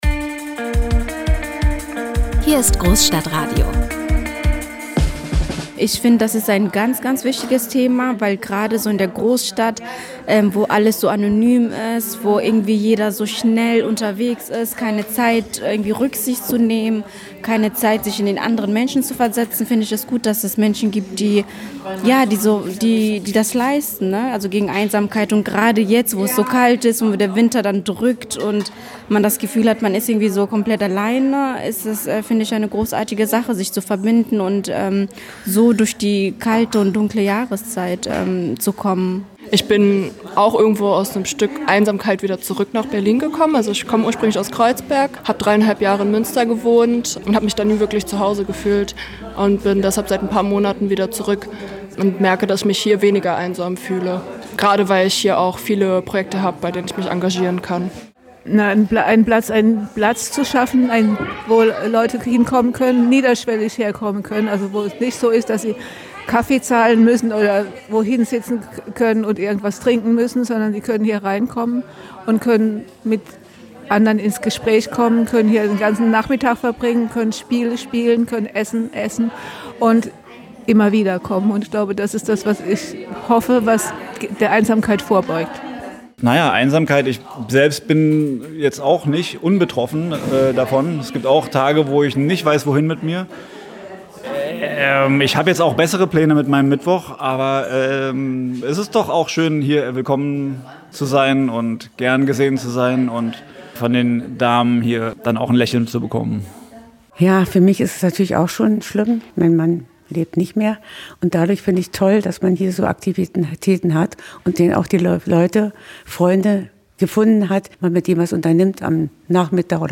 Er spricht mit Initiator*innen, Engagierten und Betroffenen – über Erfahrungen, Hoffnung und die Kraft des Miteinanders.